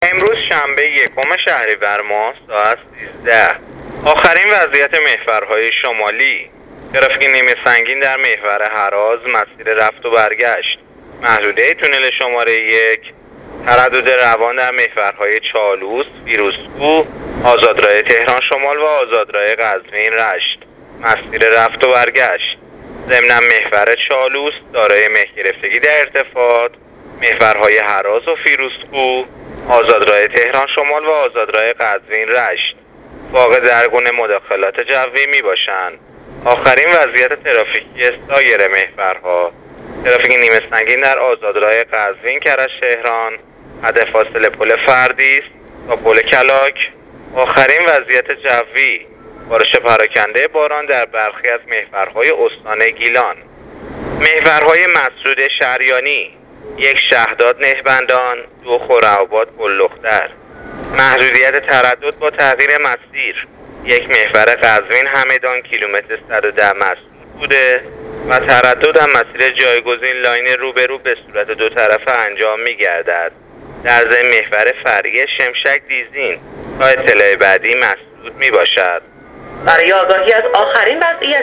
گزارش رادیواینترنتی از وضعیت ترافیکی جاده‌ها تا ساعت ۱۳ یکم شهریورماه